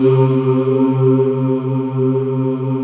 Index of /90_sSampleCDs/USB Soundscan vol.28 - Choir Acoustic & Synth [AKAI] 1CD/Partition D/01-OUAHOUAH